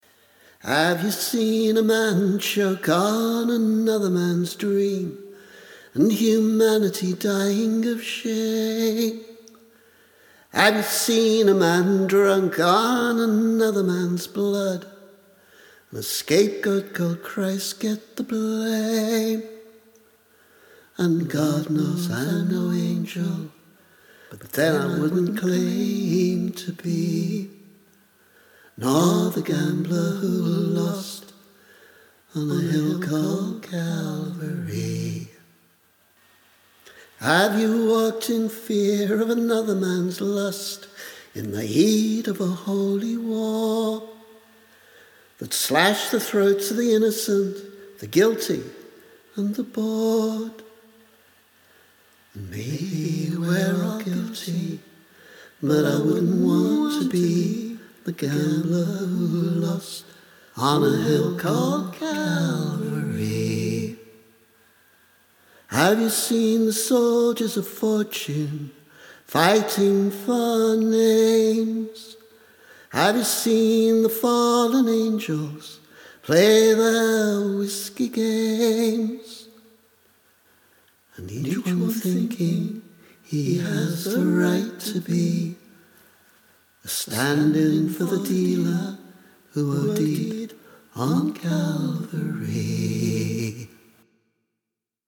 A version of this song was released on the Scriptwrecked cassette, but re-recorded here.